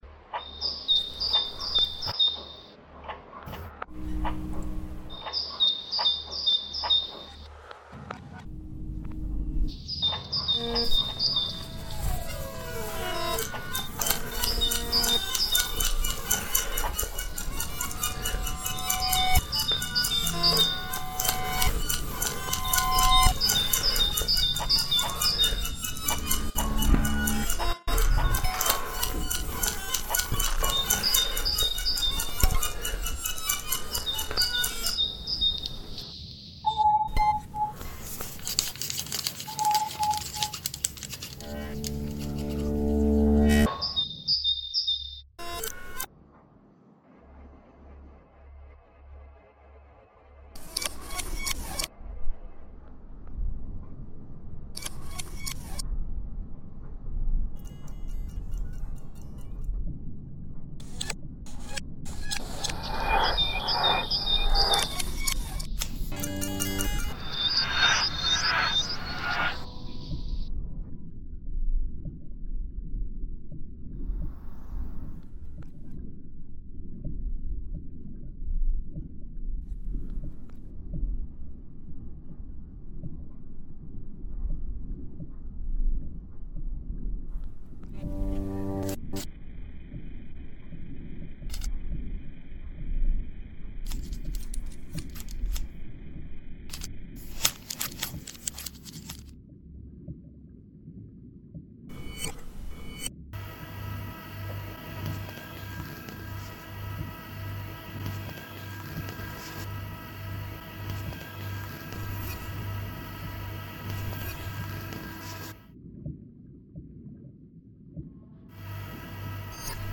Melutapahtumat konkreettisen musiikin sävellysmateriaalina.